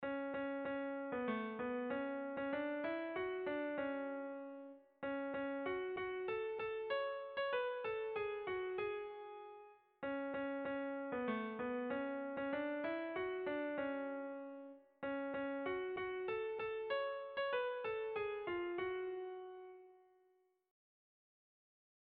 Sehaskakoa
KANTU NOSTALGIKOAK I, Urretxindorrak taldea, CD, Elkar Donostia 1993, 9 Haurra sehaskan
Zortziko txikia (hg) / Lau puntuko txikia (ip)
ABAB